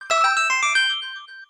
1-Up Mushroom sound effect from Super Mario 3D Land
SM3DL_1-up_SFX.mp3